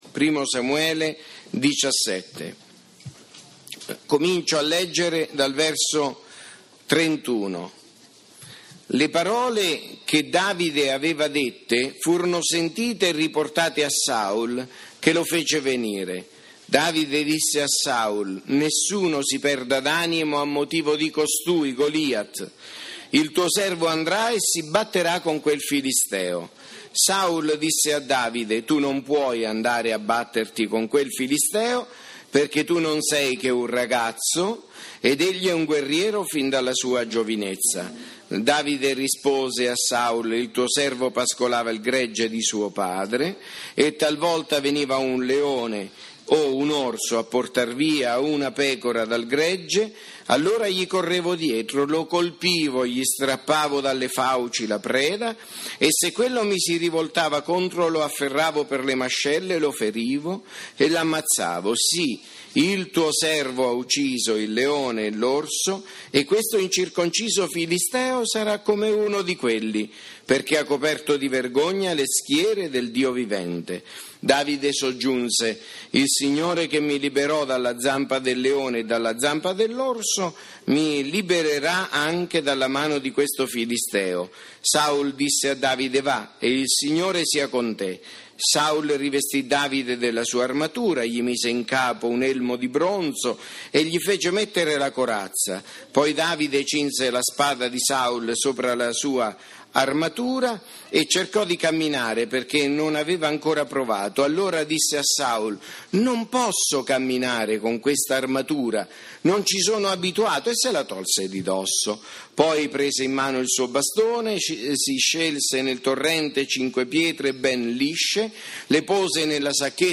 Culto di chiusura Scuola Domenicale 2015